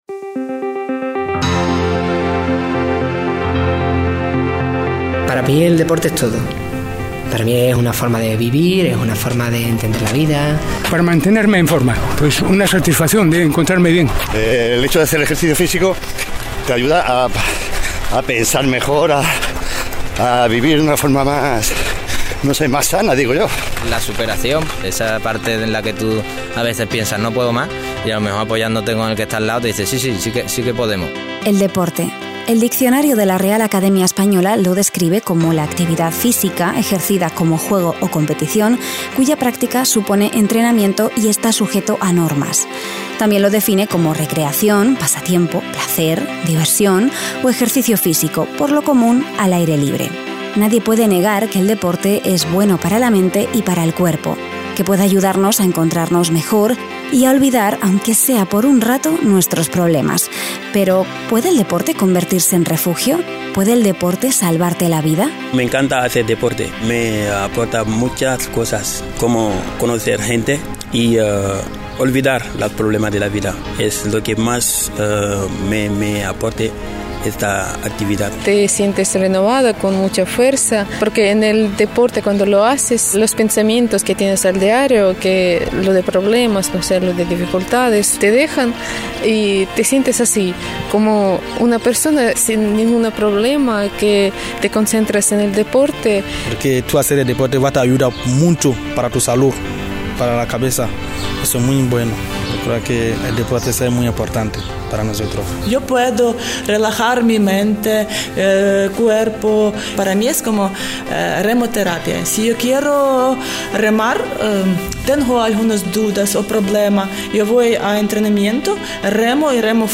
Comenzamos la temporada 8 de “Volver a empezar” para responder a la pregunta de: ¿puede el deporte convertirse en refugio? En este primer capítulo hablamos con Manizha Talash, una joven afgana bailarina de breakdance que tuvo que huir de su país cuando los talibanes tomaron el poder.